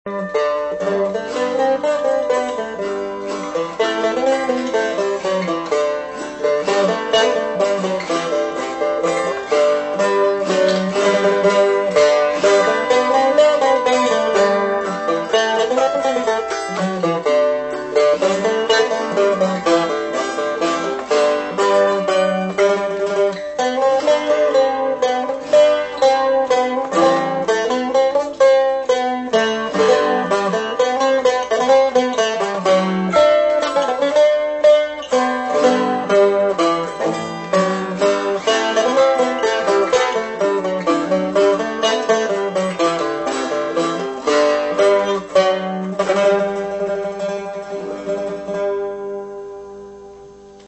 Octave Mandolin
Autoharp